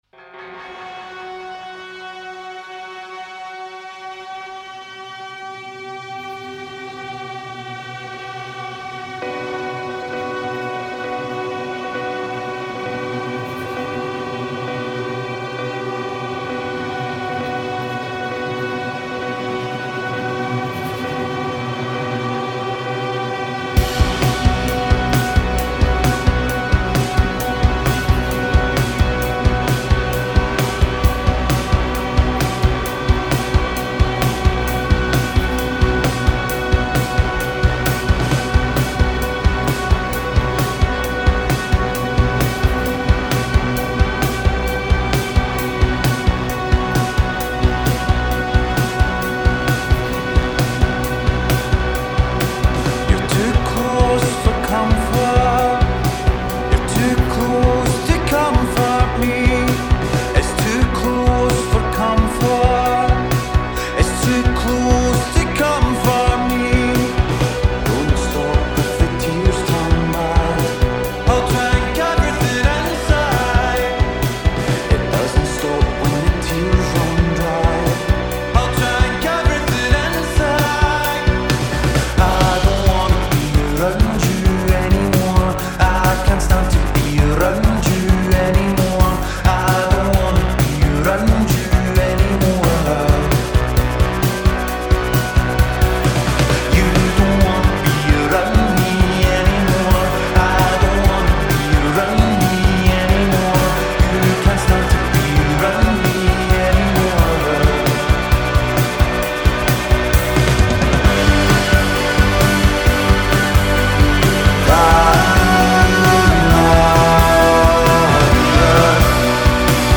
Scottish band